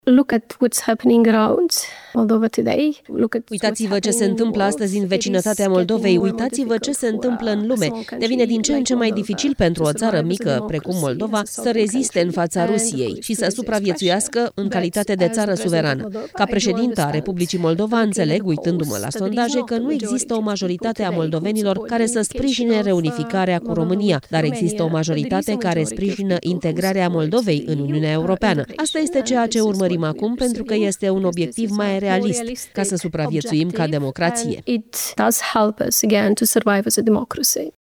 Maia Sandu a făcut aceste declarații într-un interviu pentru podcastul britanic „The Rest Is Politics: Leading”.
Președinta Republicii Moldova, Maia Sandu: „Înțeleg, uitându-mă la sondaje, că nu există o majoritate a moldovenilor care să sprijine reunificarea țării cu România”